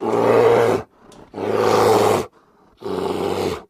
Kodiak Beak Growls More Exhausted